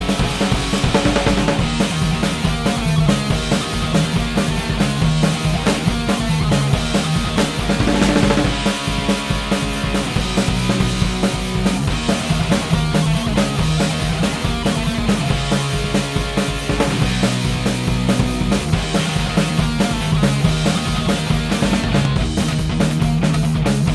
Minus Solo Guitar Rock 2:45 Buy £1.50